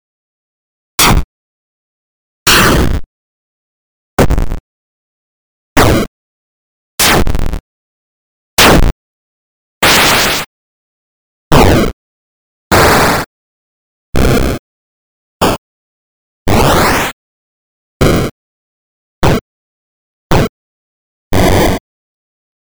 8-Bit Explosion Sound Effects (Copyright Free)
15 high quality, copyright-free 8-bit explosion sound effects.
8bit-explosion-sound-effects.mp3